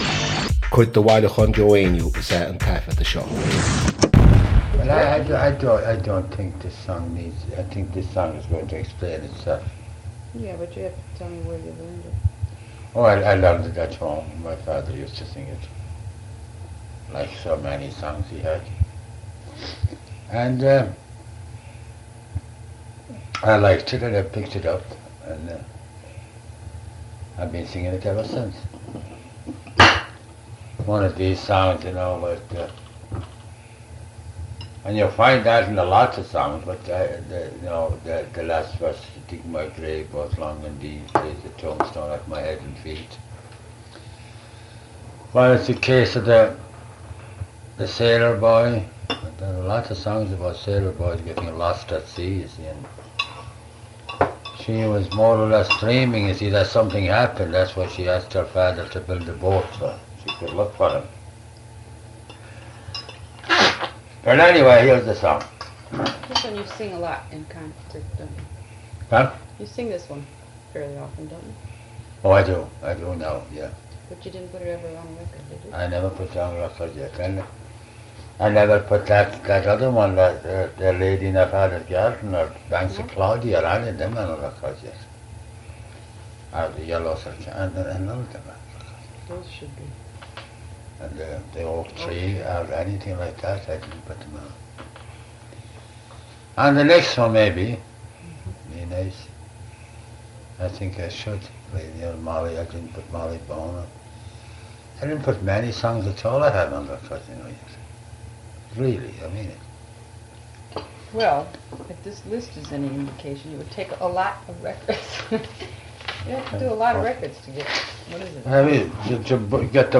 • Catagóir (Category): song.
• Ainm an té a thug (Name of Informant): Joe Heaney.
• Suíomh an taifeadta (Recording Location): Bay Ridge, Brooklyn, New York, United States of America.